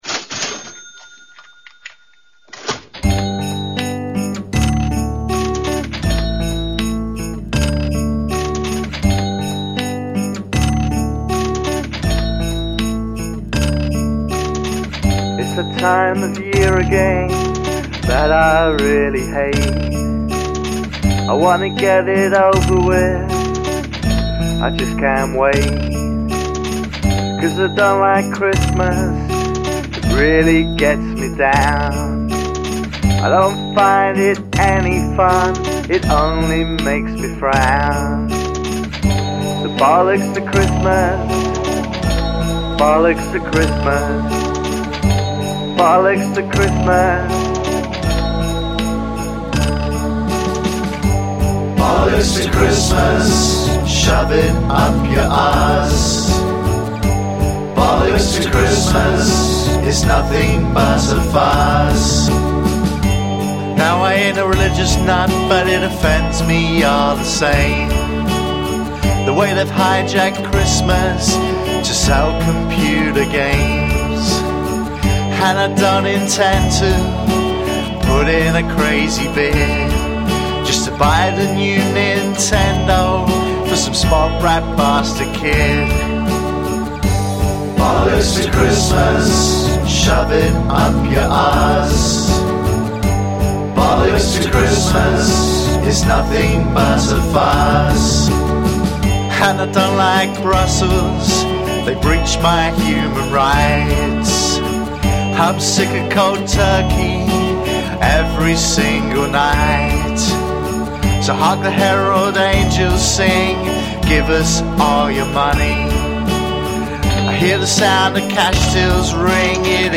A post punk pop group from Cornwall